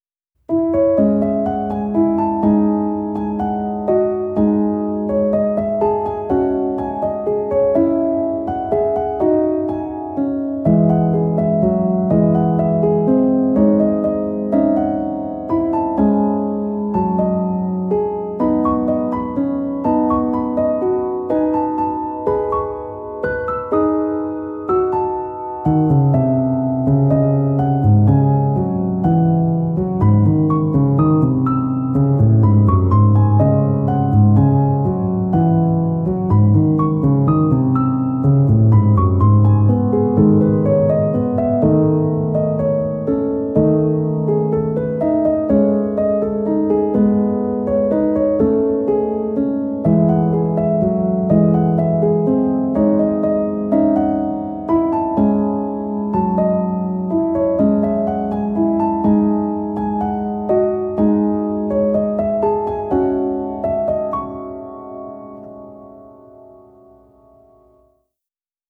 PIANO T-Z (21)